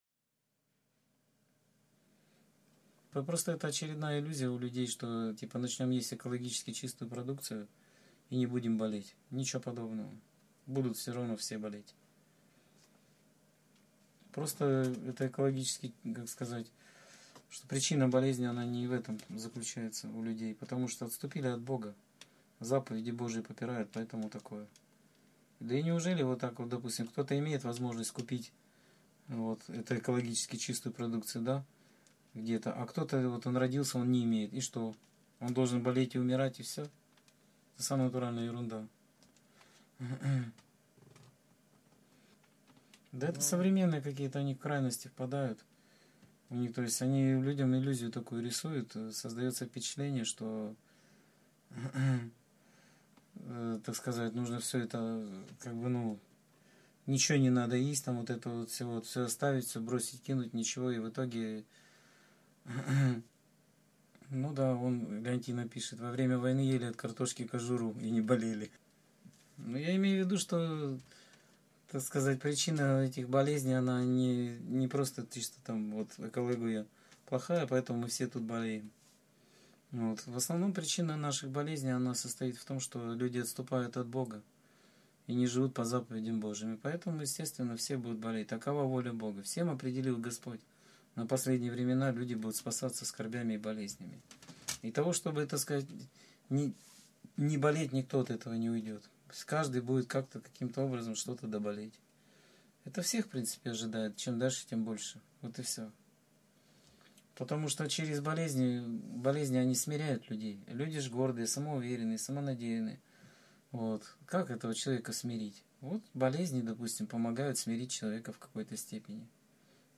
О символах и отношении к ним (Скайп-беседа 20.07.2013) — ХРИСТИАНСКАЯ ЦЕРКОВЬ